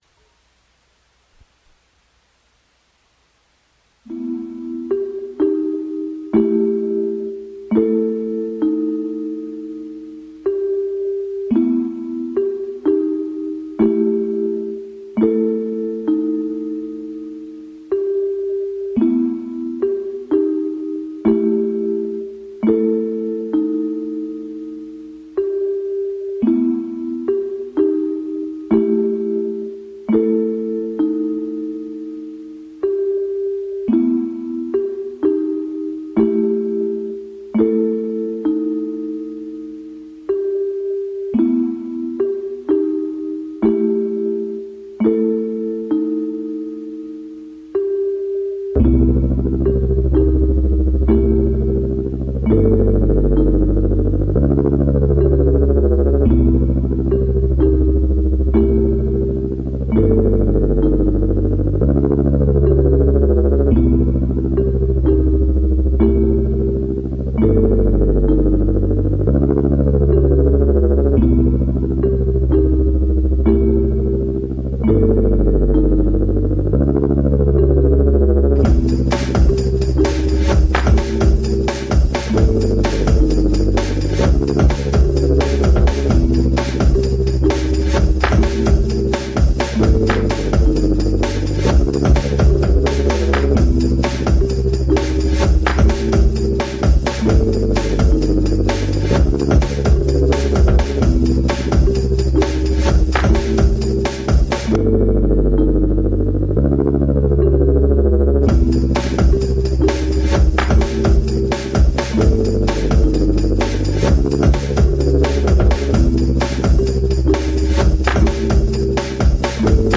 Old, weird stuff.